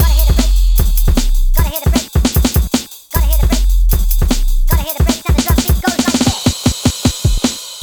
33 Drumbeat Goes-b.wav